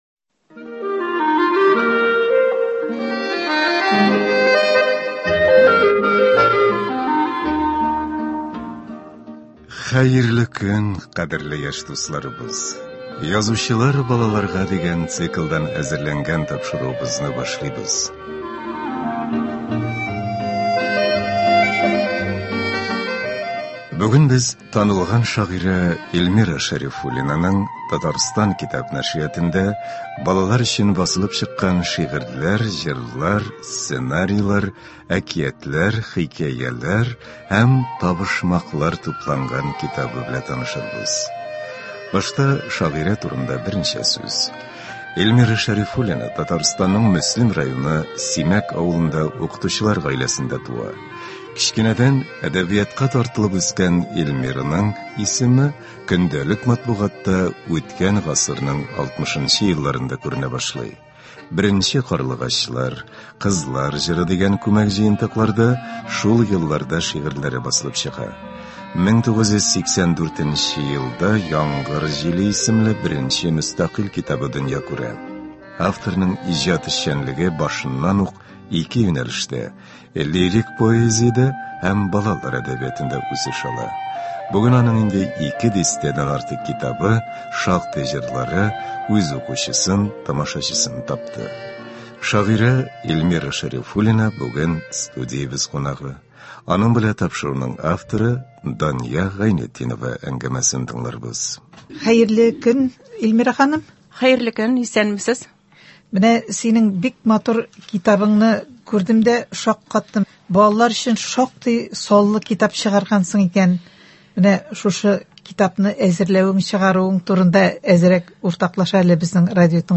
Бүгенге тапшыруыбызда танылган шагыйрә Эльмира Шәрифуллина иҗаты белән танышырбыз, авторның үзе укуында кайбер әсәрләрен ишетербез.